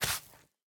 brushing_gravel1.ogg